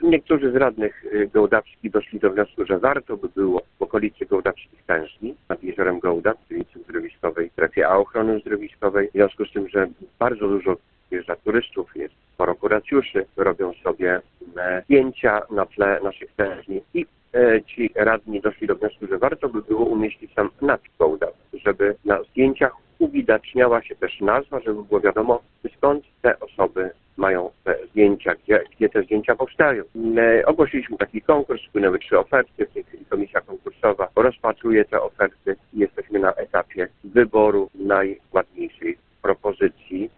-Konstrukcja ma stanąć w pobliżu największej atrakcji turystycznej miasta, czyli tężni solankowej, mówi Jacek Morzy, zastępca burmistrza Gołdapi.